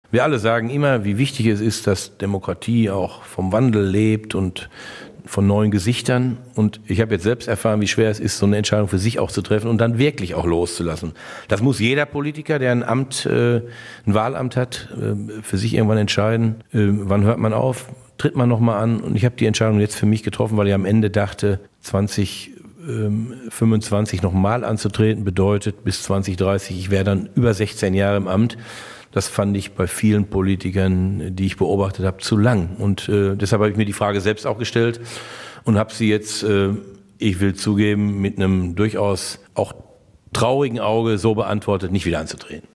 Bei der nächsten Kommunalwahl im Herbst 2025 wird Schulz nicht mehr als Kandidat für das Amt auf dem Wahlzettel stehen. Die Entscheidung sei über den Jahreswechsel gefallen, sagte der OB im Radio-Hagen-Gespäch.